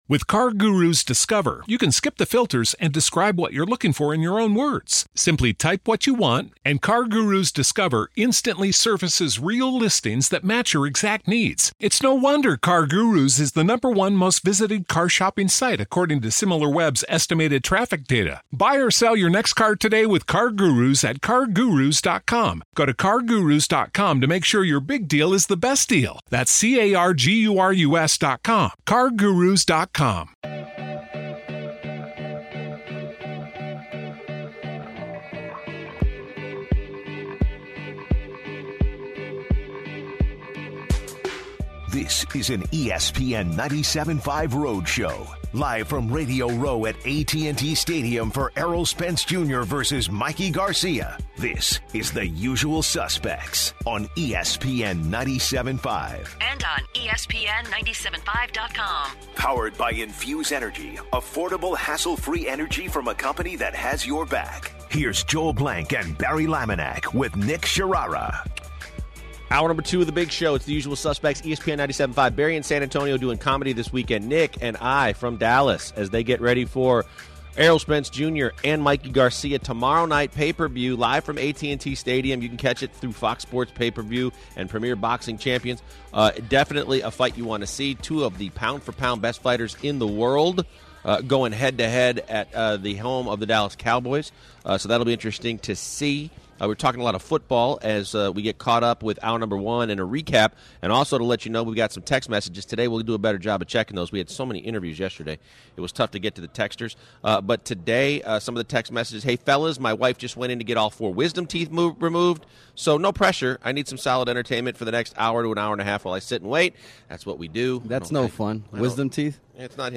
The guys start the second hour live at AT&T Stadium in Arlington for the big fight. The guys continue the NFL happenings as free agency show more and more players being signed.